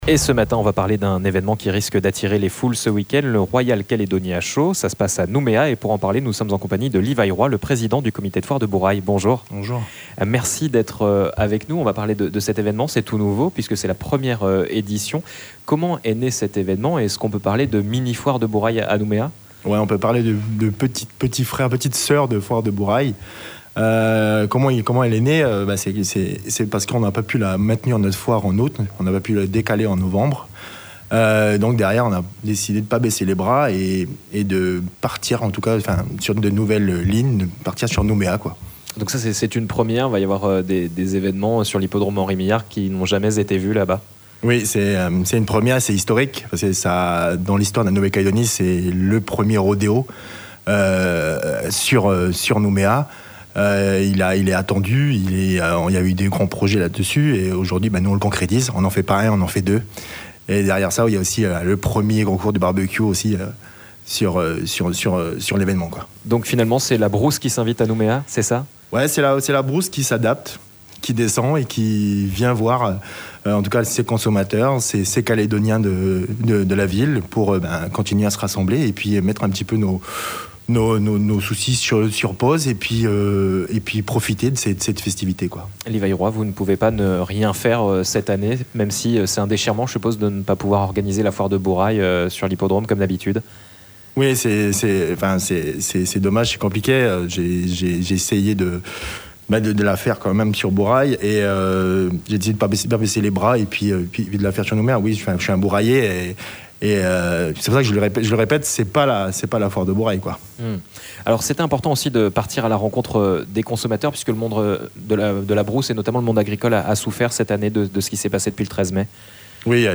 L'INVITÉ DU MATIN